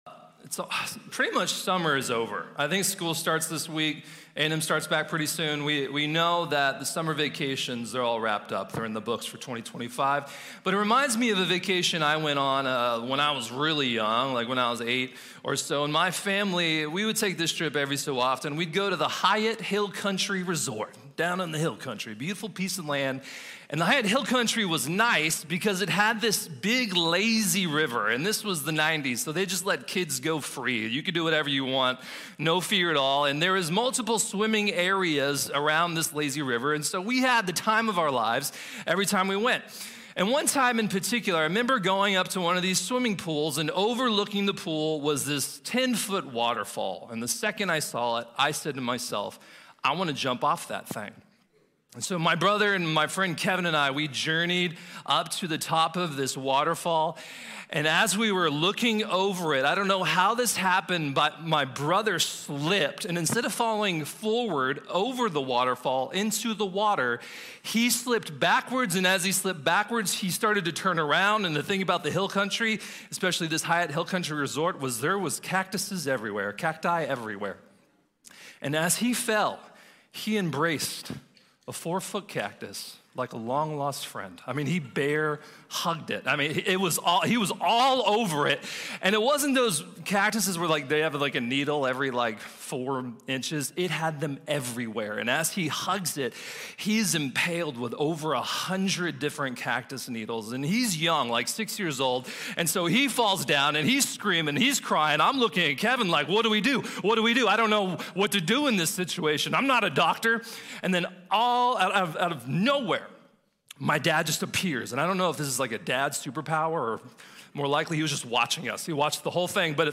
Joshua | Sermon | Grace Bible Church